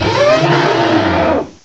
cry_not_mamoswine.aif